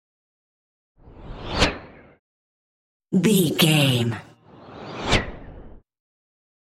Whoosh bright airy fast x2
Sound Effects
bright
whoosh
sci fi